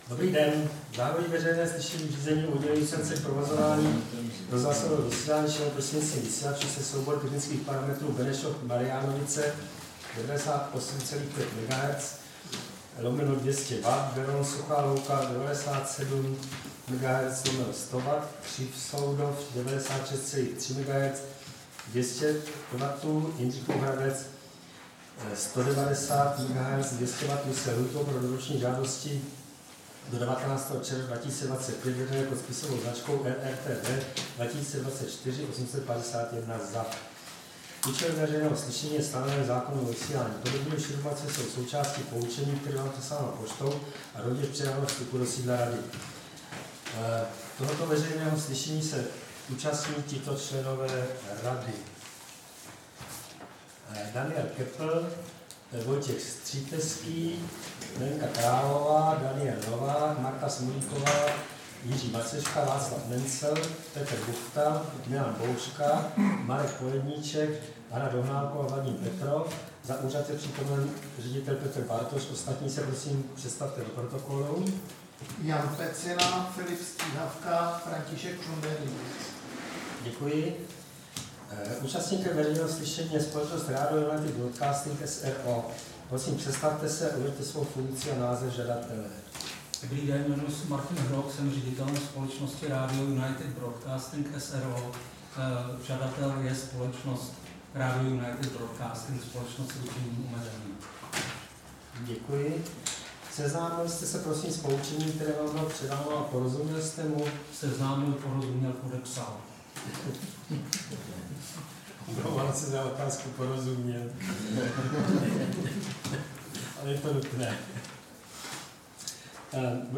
Veřejné slyšení v řízení o udělení licence k provozování rozhlasového vysílání šířeného prostřednictvím vysílačů se soubory technických parametrů Benešov-Mariánovice 98,5 MHz/200 W, Beroun-Suchá louka 97,0 MHz/100 W, Křivsoudov 96,3 MHz/200 W, Jindřichův Hradec 90,0 MHz/200 W
Místem konání veřejného slyšení je sídlo Rady pro rozhlasové a televizní vysílání, Škrétova 44/6, 120 00 Praha 2.